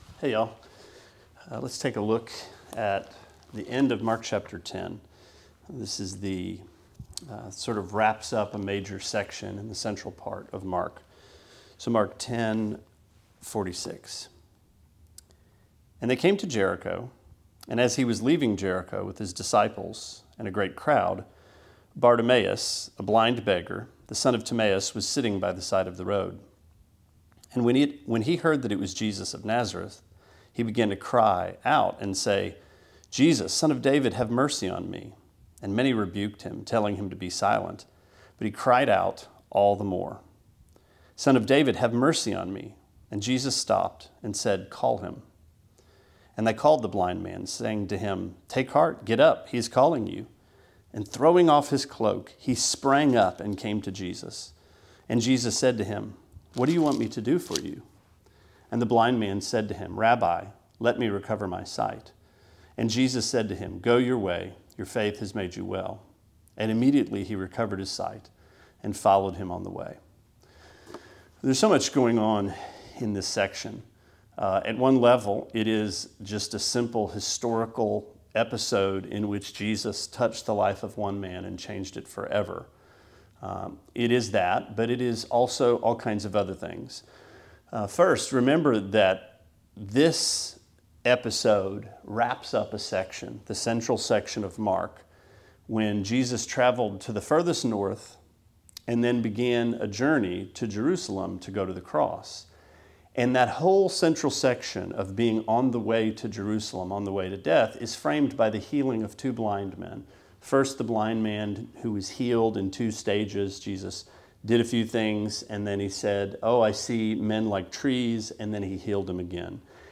Sermonette 7/26: Mark 10:46-52: The Blind See